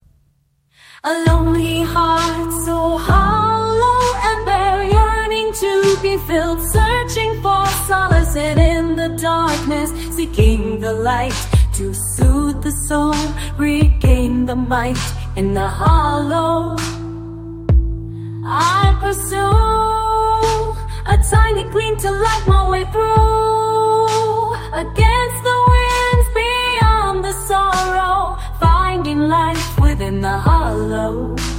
Музыка нейросетей
Песня на английском